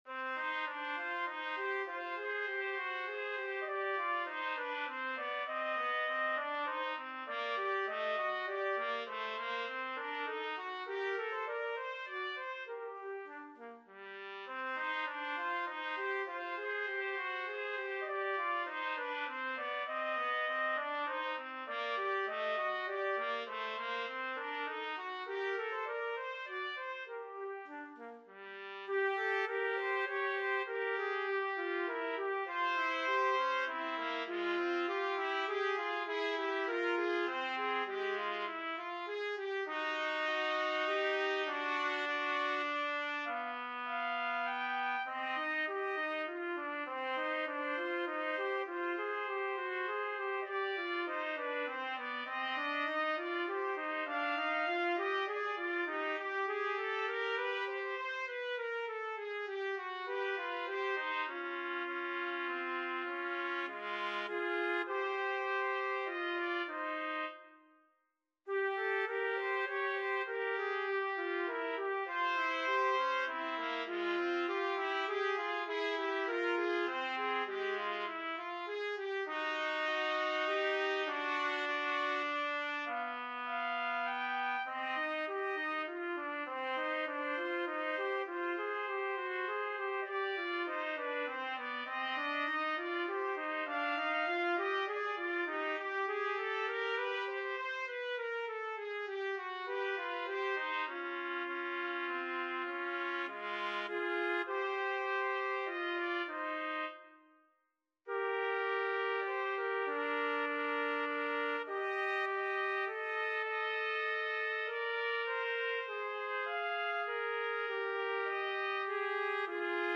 Trumpet 1Trumpet 2
3/4 (View more 3/4 Music)
Moderato
Classical (View more Classical Trumpet Duet Music)